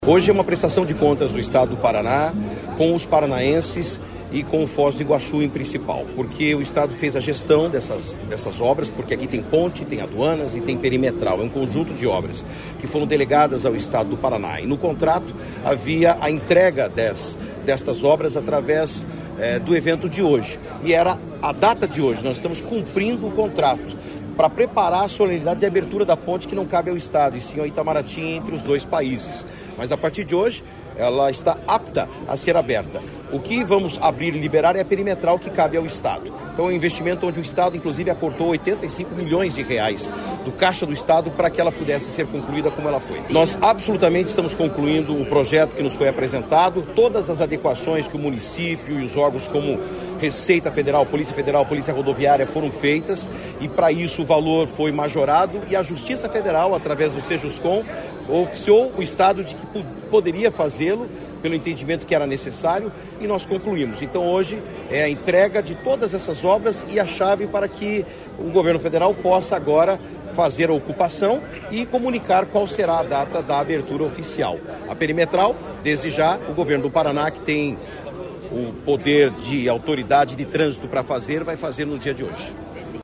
Sonora do secretário Estadual da Infraestrutura e Logística, Sandro Alex sobre a abertura da Perimetral Leste, em Foz